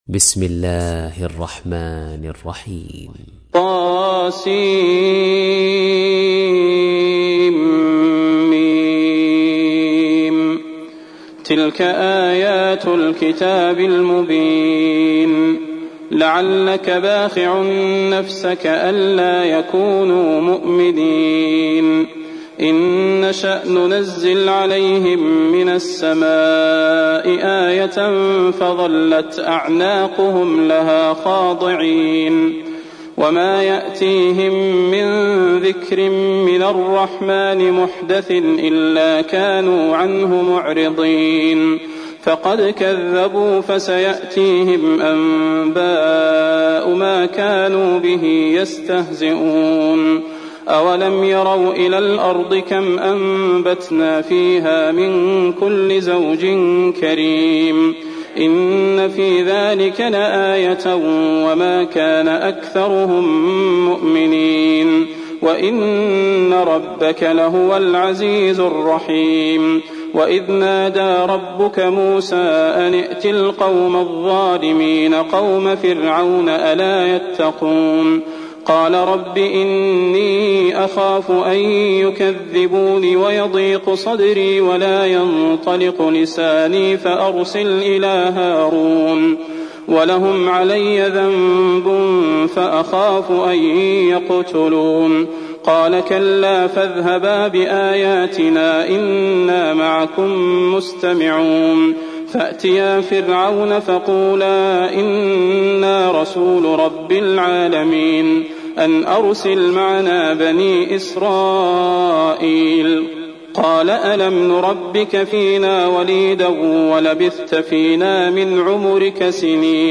تحميل : 26. سورة الشعراء / القارئ صلاح البدير / القرآن الكريم / موقع يا حسين